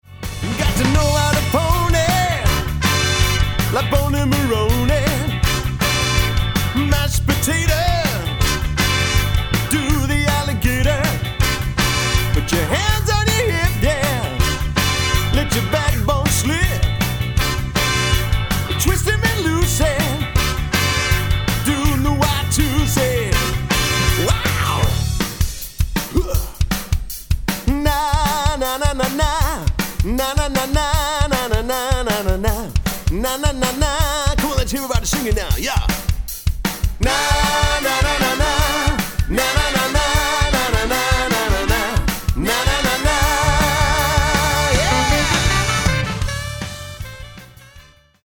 11 piece rhythm and blues band